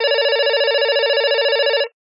Рингтон Обычный звонок офисного телефона